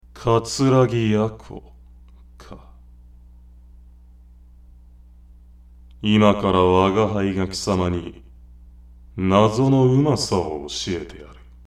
音声素材
声職人さん方から提供された音声素材です。